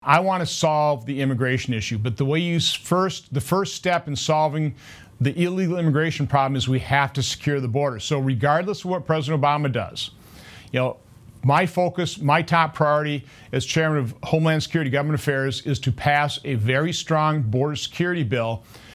Senator Johnson gave these answers during an interview on Wednesday, Nov. 12, with WFRV-TV